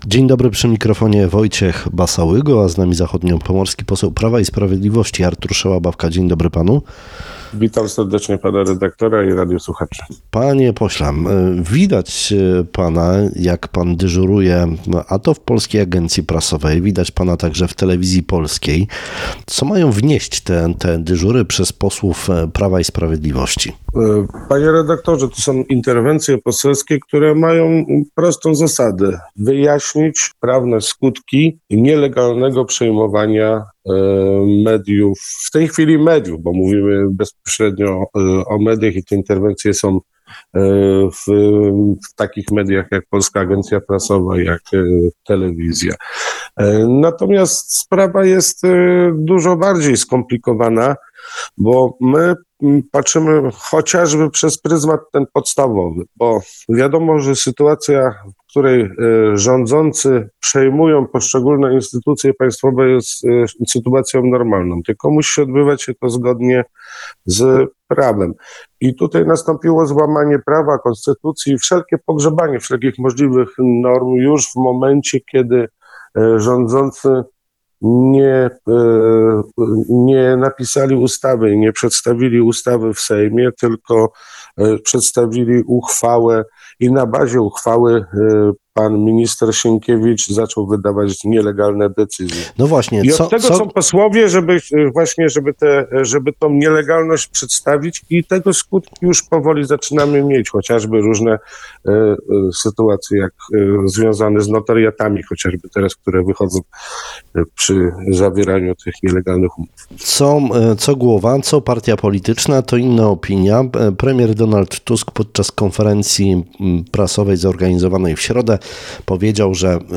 Mówi zachodniopomorski poseł PiS – Artur Szałabawka, poranny gość Rozmowy Dnia.